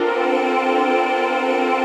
XXL 800 Pads
VOICEPAD09-LR.wav